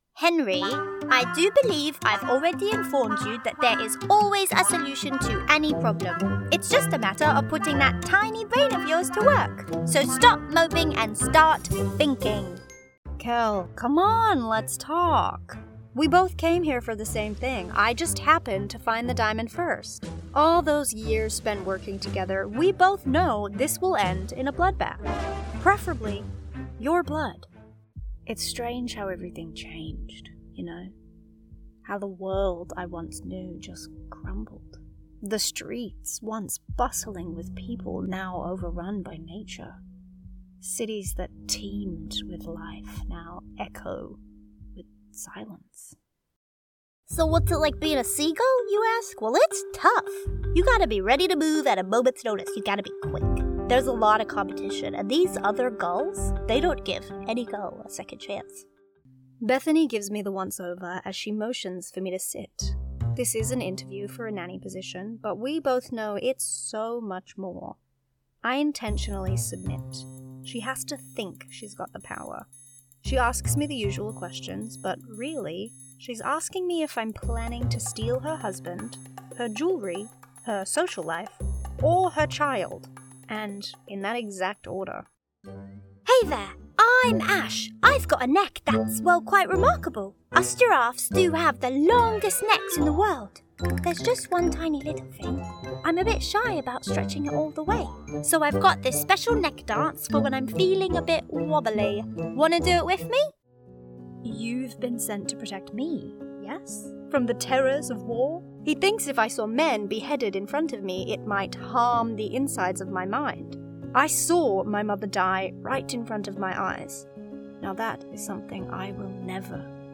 Female
My vocal quality is bright, authentic and engaging.
I record in a sound treated studio at home using a Rode NT USB+ microphone.
Character / Cartoon
Characters And Accents